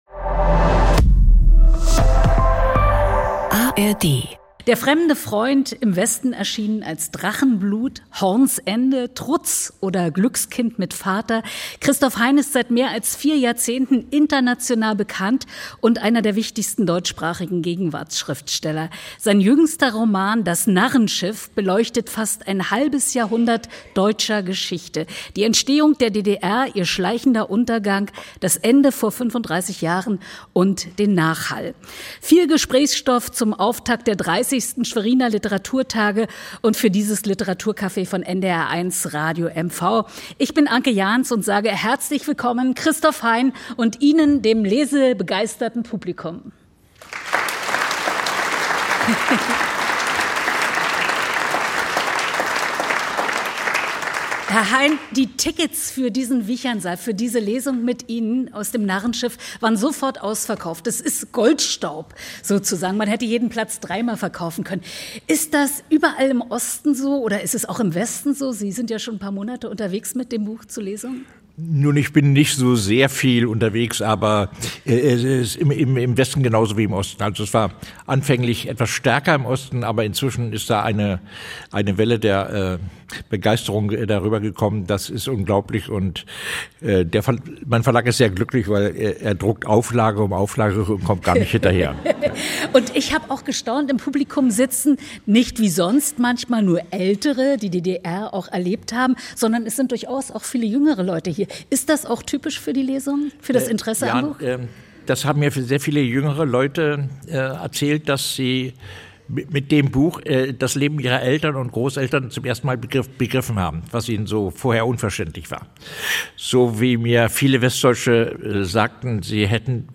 Beschreibung vor 6 Monaten Christoph Hein singt mit dem Schweriner Publikum "Das Lied von der Loreley". Er berührt im Literaturcafé von NDR1 Radio MV am 10.10.2025 auch mit Geschichten aus seinem Buch " Alles was Du brauchst- die zwanzig wichtigsten Dinge".Wird er nach dem Roman "Das Narrenschiff"